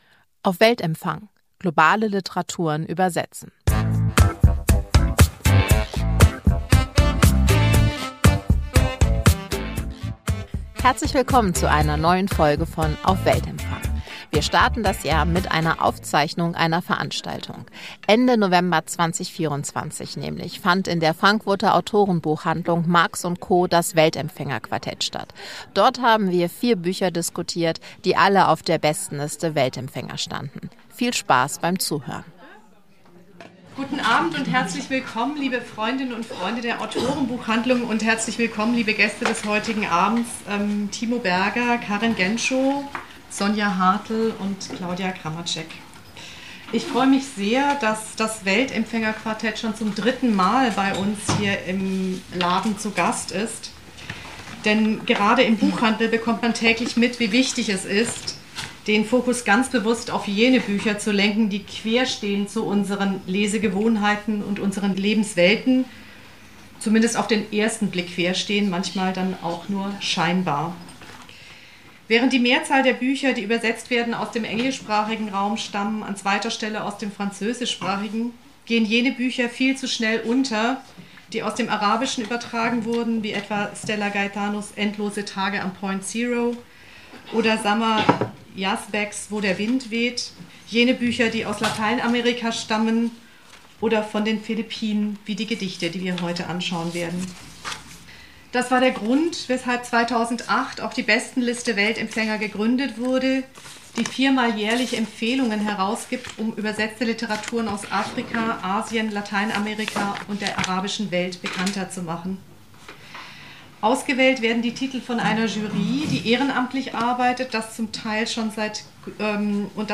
4 Bücher, 4 Meinungen – Aufzeichnung vom Weltempfänger-Quartett 2024 ~ Litprom auf Weltempfang - Globale Literaturen übersetzen Podcast